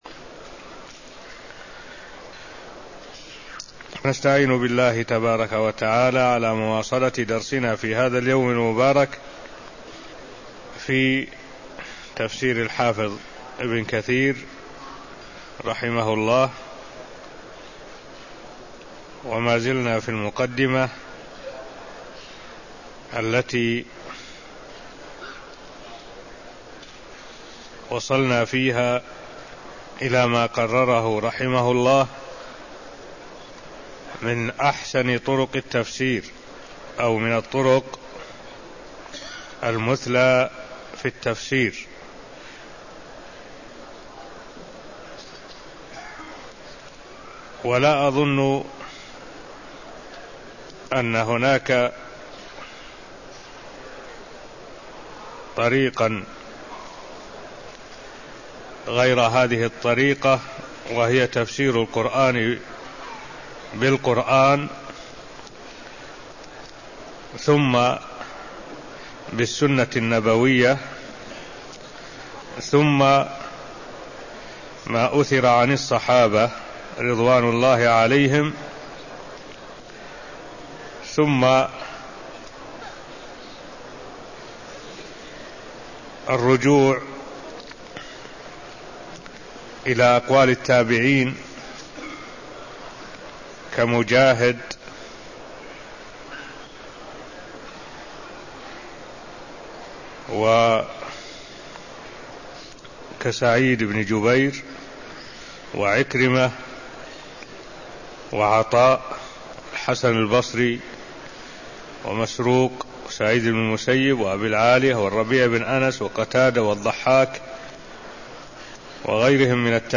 المكان: المسجد النبوي الشيخ: معالي الشيخ الدكتور صالح بن عبد الله العبود معالي الشيخ الدكتور صالح بن عبد الله العبود شرح مقدمة تفسير ابن كثير (0003)